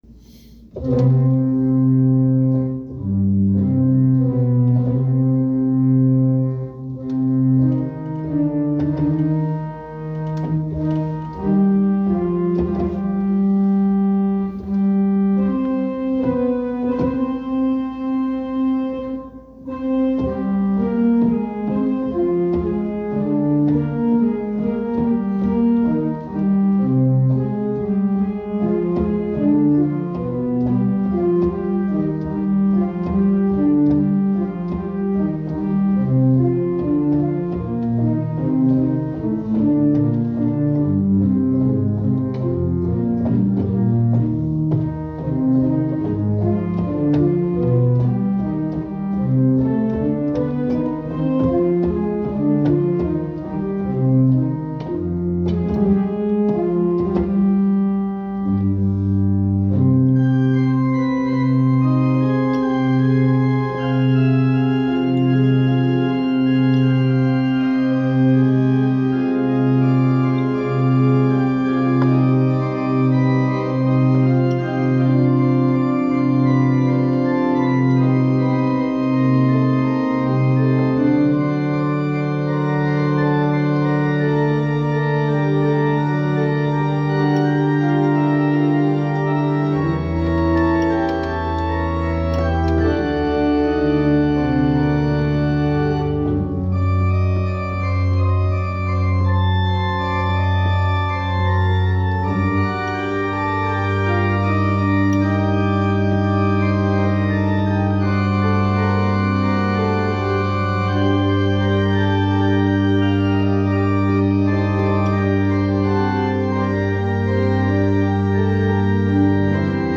Praeludium of BWV 549 is finally more or less done (sorry for all the clacking noises).:win: The Fugue should follow soon, once I get the hang of the pedal part.
The “clacking noises” just adds authentic organist ambience it’s called texture.